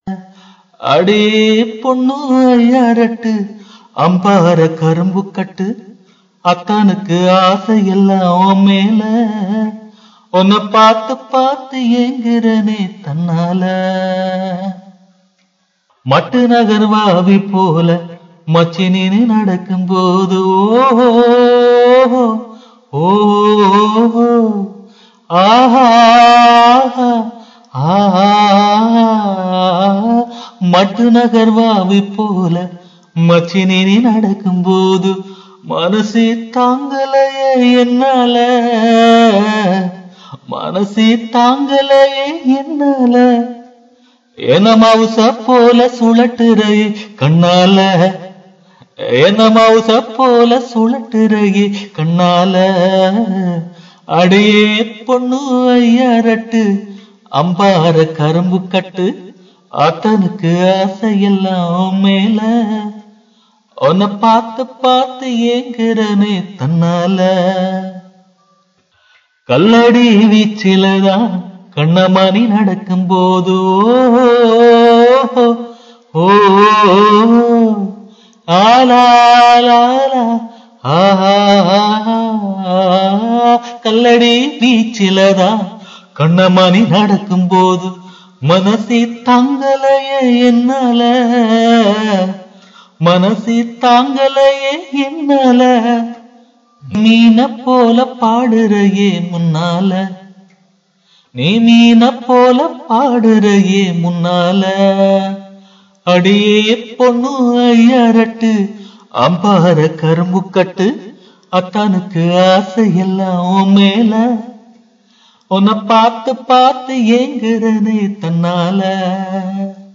A folk song of Batticaloa
After long time I written a poem and sang it in my voice with using of pure rural words... listen please